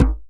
DJEM.HIT05.wav